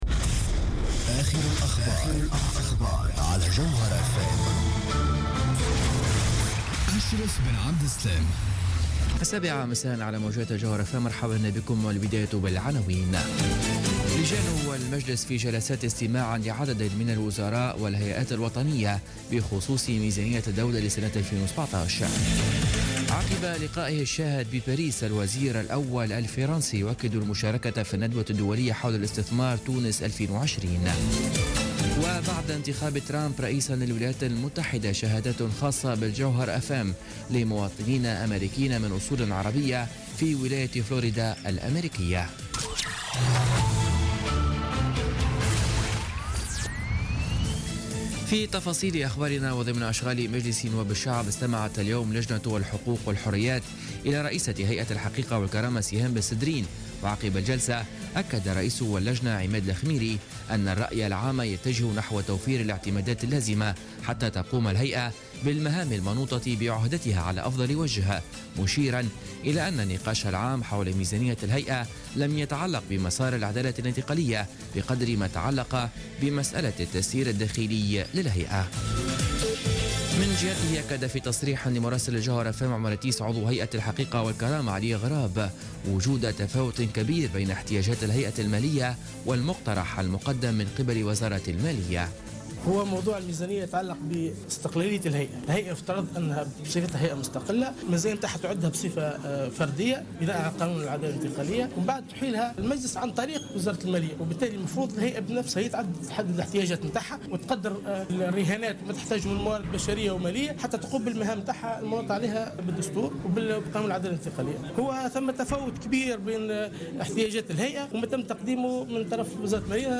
Journal Info 19h00 du mercredi 9 novembre 2016